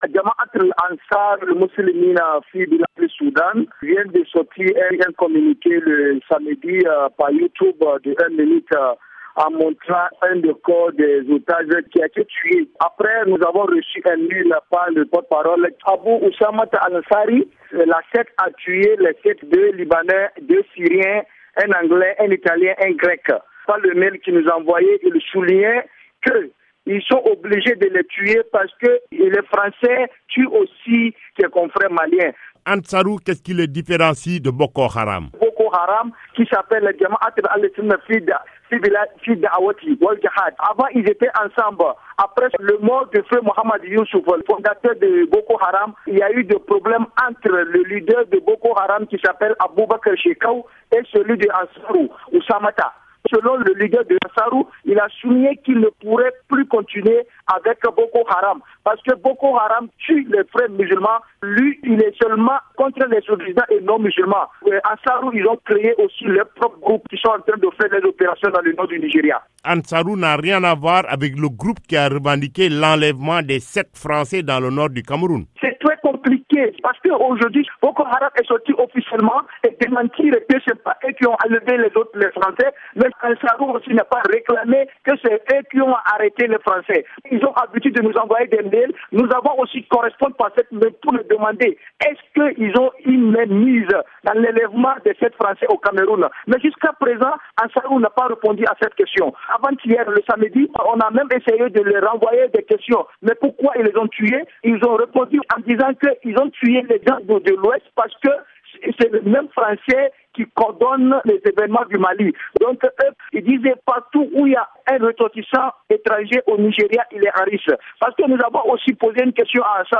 Le journaliste